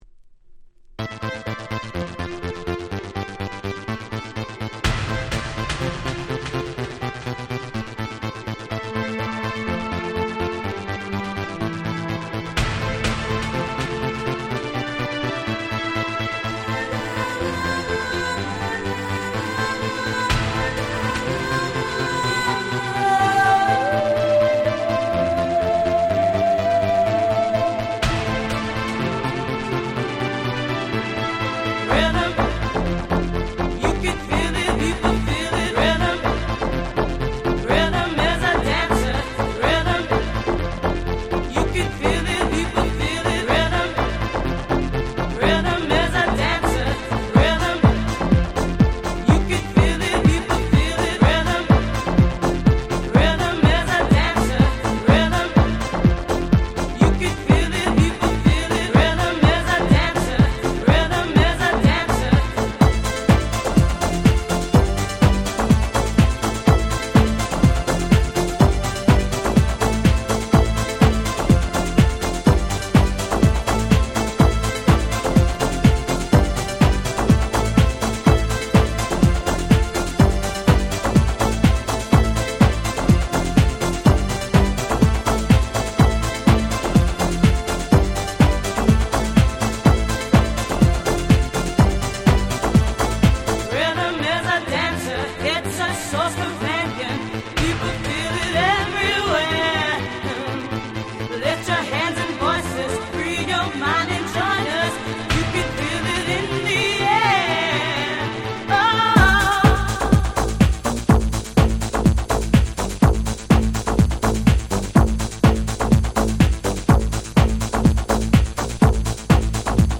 92' Super Euro Dance Classics !!
ユーロダンスポップ House ハウス